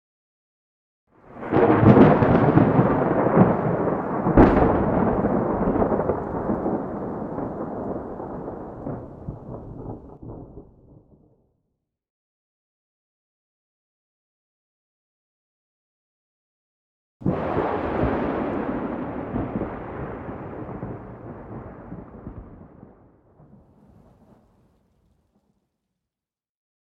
new_thunder1_hec.ogg